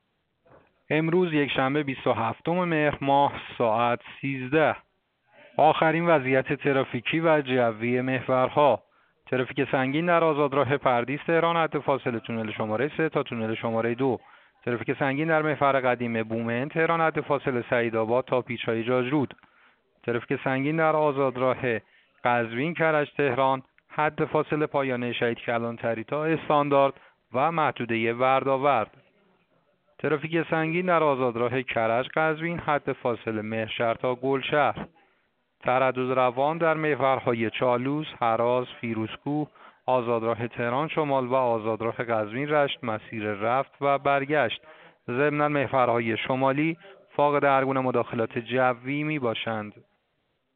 گزارش رادیو اینترنتی از آخرین وضعیت ترافیکی جاده‌ها ساعت ۱۳ بیست‌وهفتم مهر؛